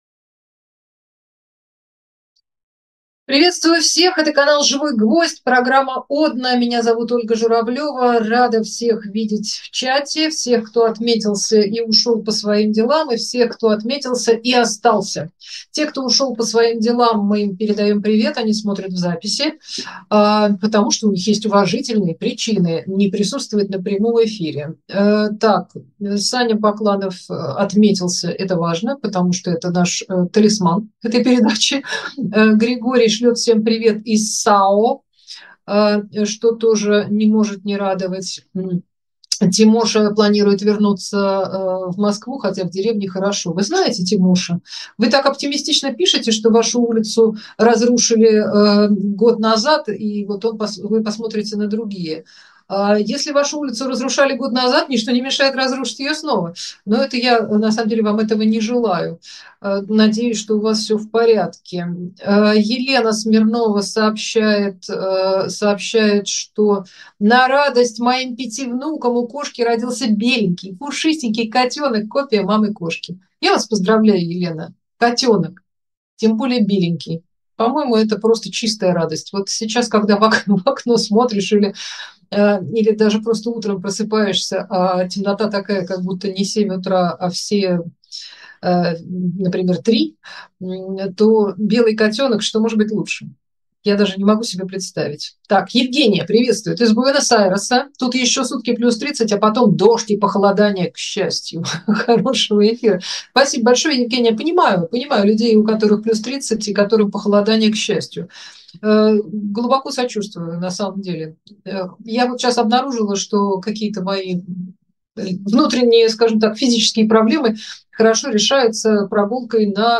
Эфир ведет Ольга Журавлева